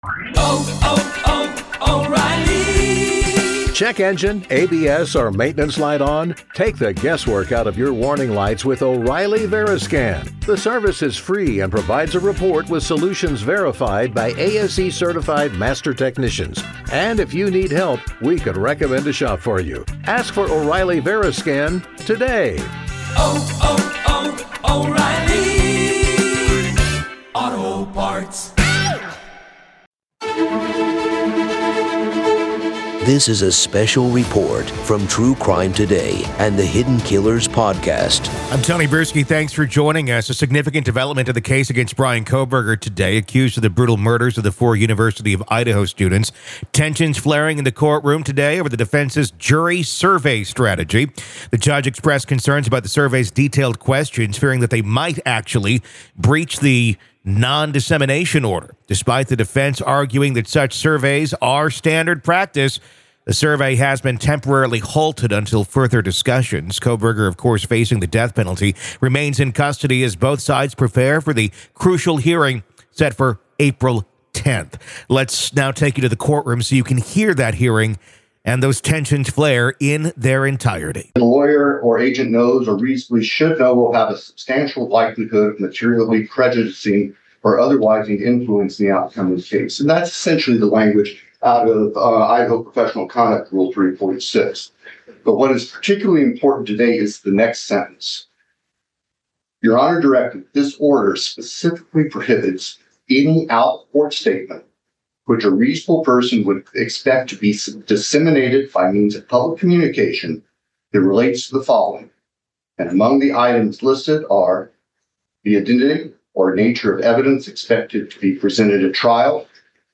COURT -Heated Courtroom Kohberger Hearing Over Defense's Un-Approved Jury Questionnaire